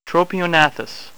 Pronunciation Key
TRO-pe-o-NA-thus